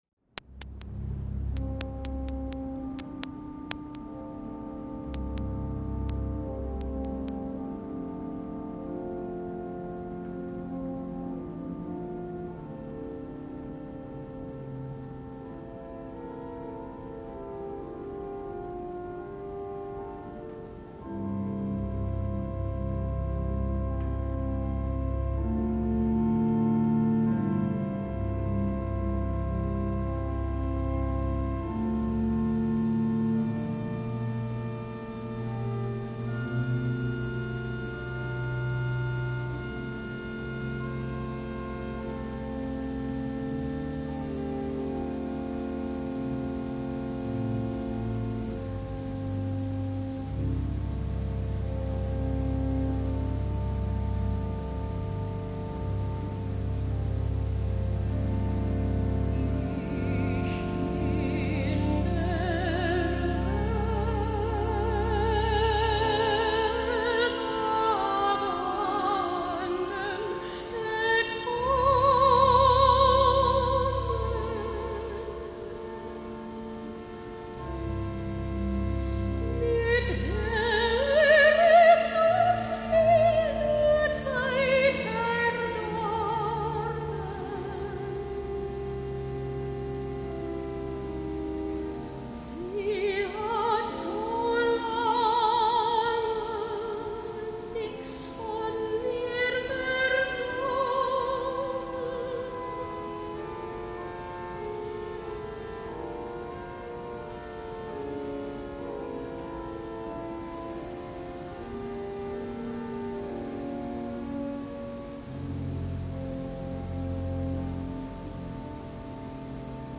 voice
organ
joyful, ethereal singing.